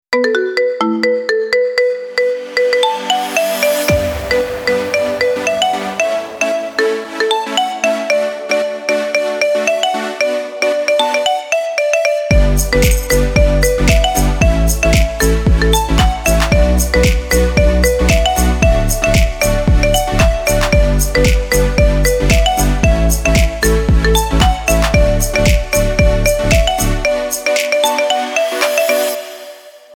Categoria Marimba Remix